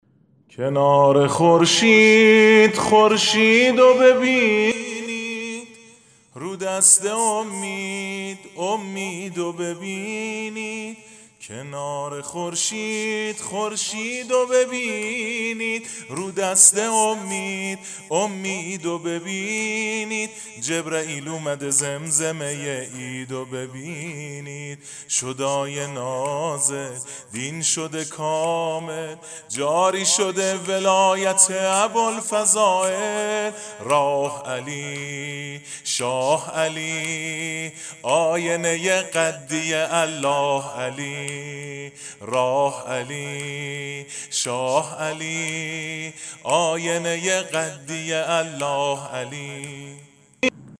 عید غدیر
سرود